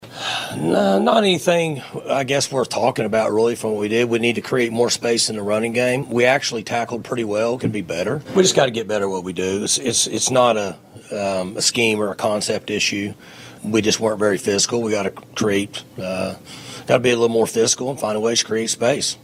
Oklahoma State head football coach Mike Gundy met with the media on Monday in Stillwater, as the Cowboys eye one of their biggest tests of the season.